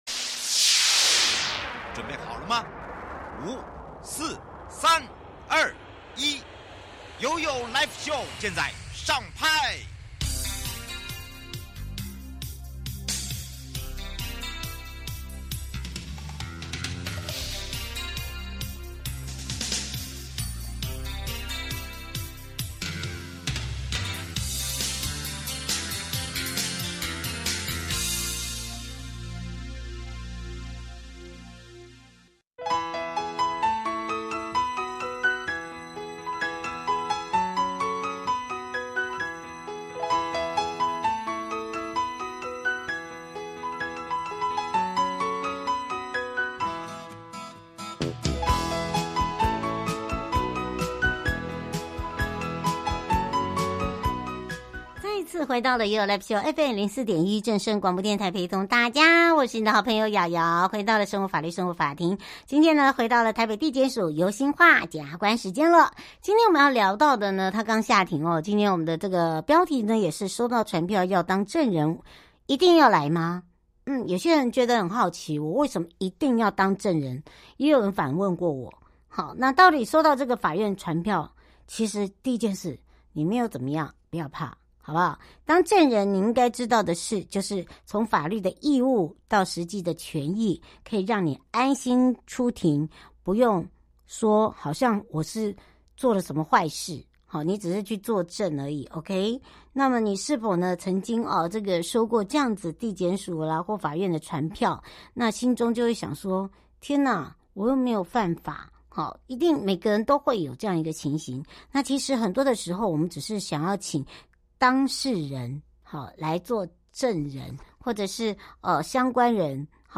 受訪者： 臺北地檢署 游欣樺檢察官 節目內容： 主題：收到傳票要當證人，我必須去嗎？